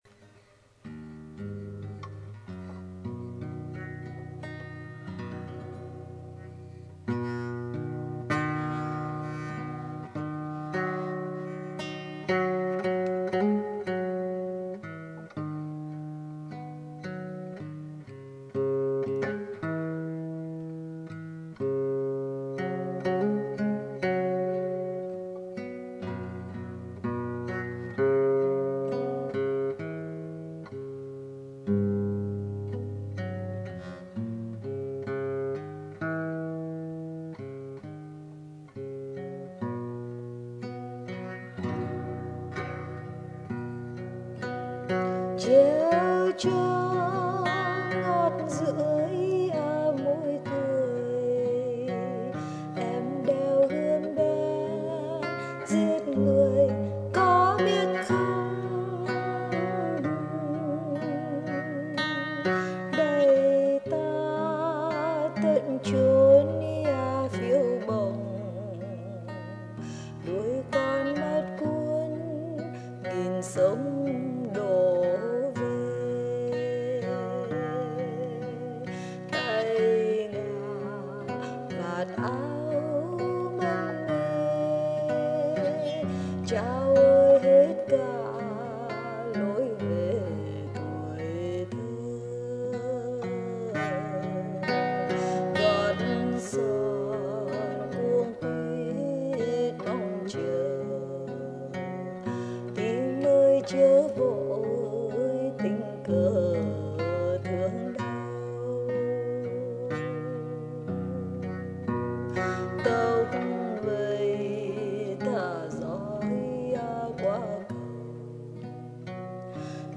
con nhỏ ghi xuống xong cầm đàn lên hát
cứ gam la thứ mà bấm, tính la thứ – rê thứ – mi bảy là từng tứng tưng hát loạn